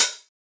• Damped Hat Sound B Key 80.wav
Royality free hihat sound tuned to the B note. Loudest frequency: 7829Hz
damped-hat-sound-b-key-80-SD2.wav